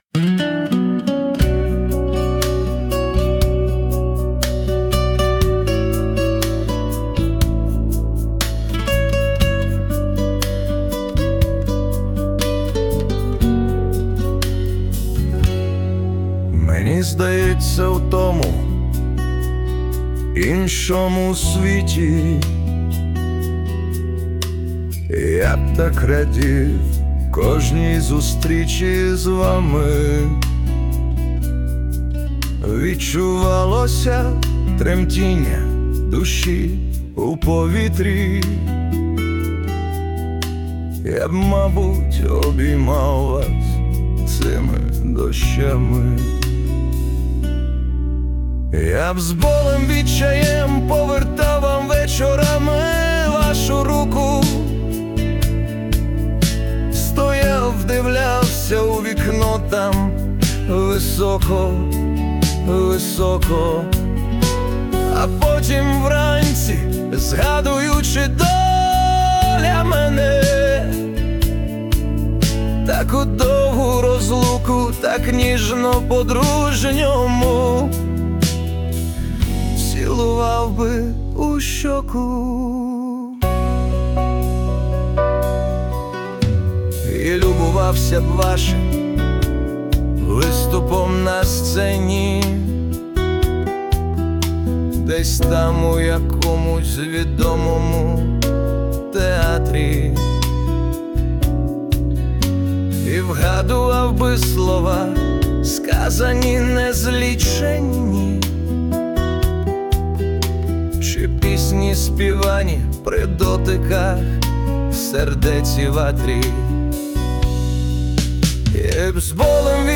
Присутня допомога SUNO у виконанні
СТИЛЬОВІ ЖАНРИ: Ліричний